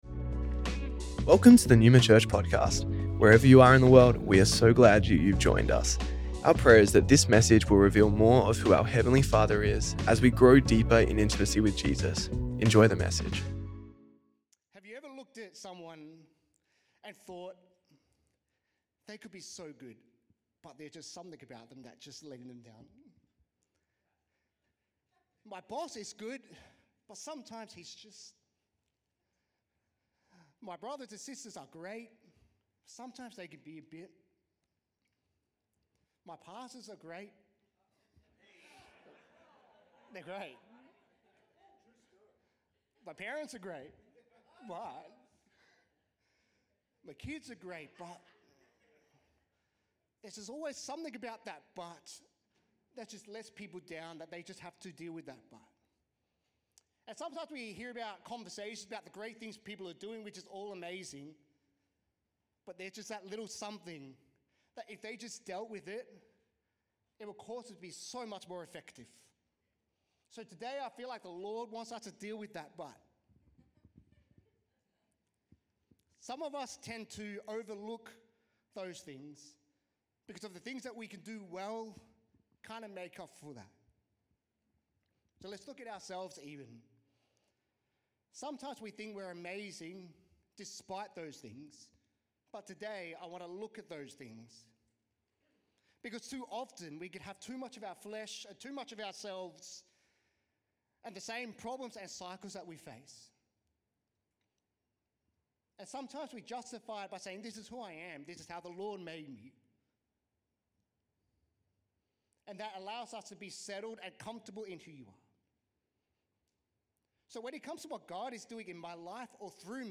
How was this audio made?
Neuma Church Melbourne South Originally recorded at the 10AM Service on Sunday 17th August 2025